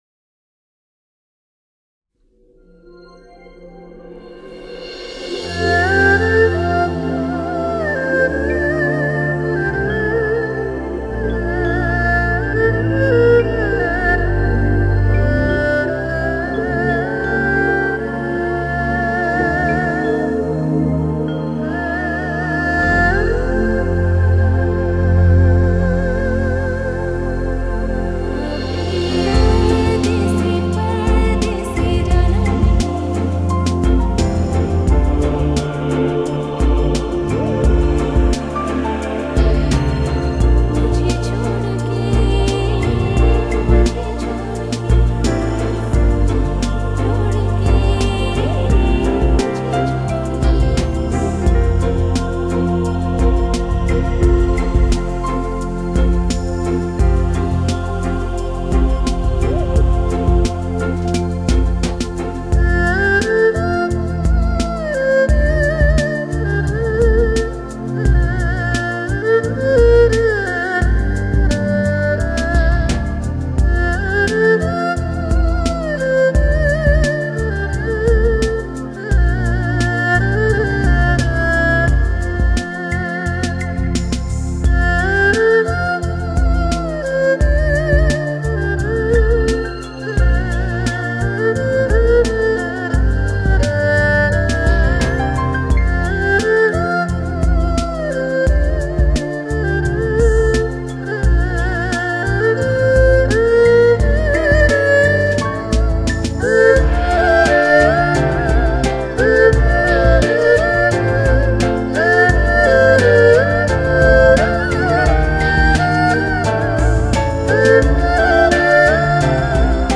Genre: Chinese Pop, Instrumental, Erhu